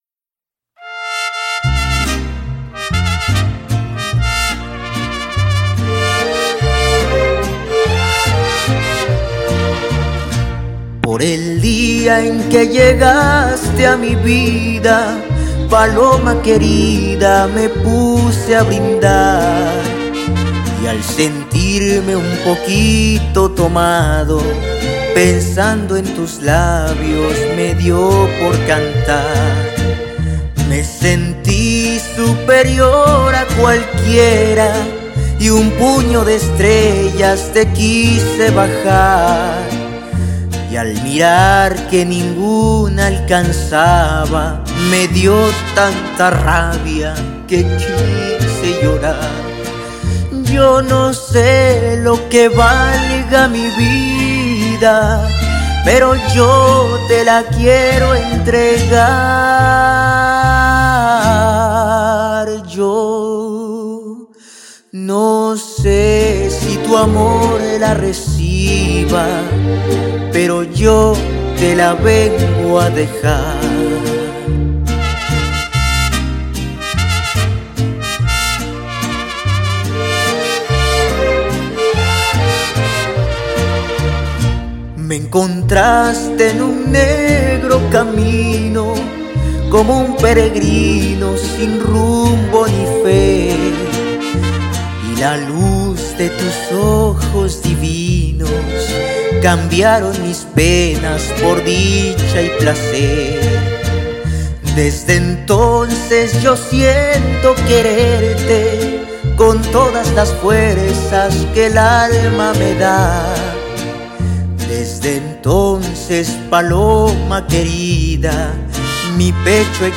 No canto tan bién (Aunque muchos digan lo contrario).